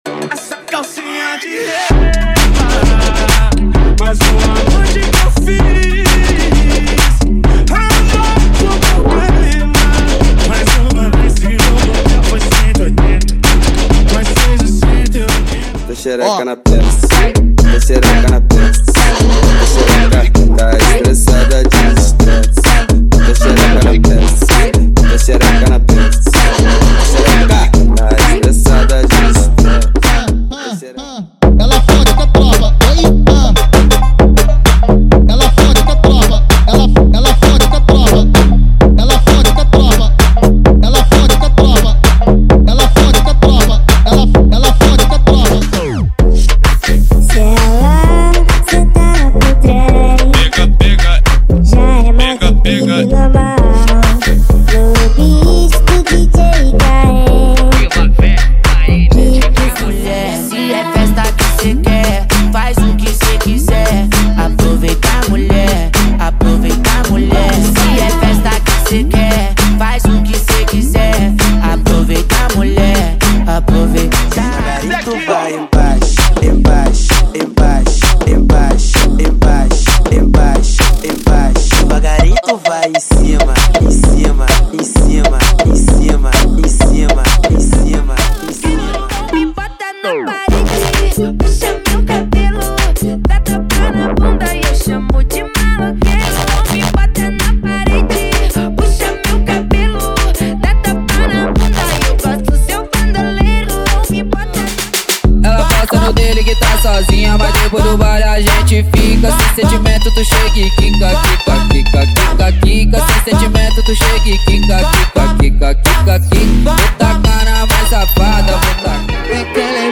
50 músicas Eletro Funk atualizadas
✅ Músicas sem vinhetas
Alta qualidade de áudio